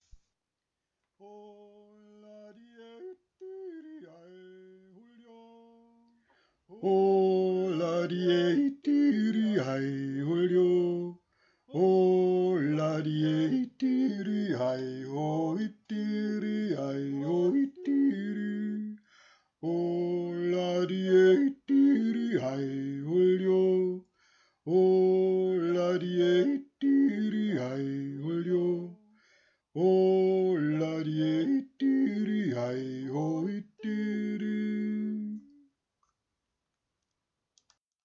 3. Stimme